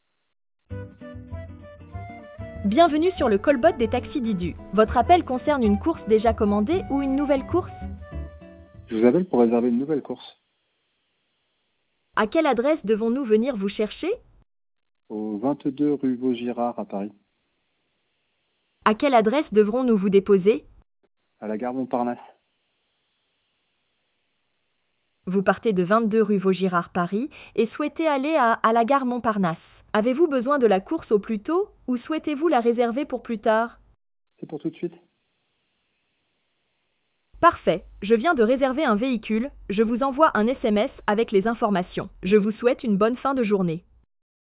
Listen to our various callbot demos and try them out for yourself by dialing the numbers shown (at no extra cost).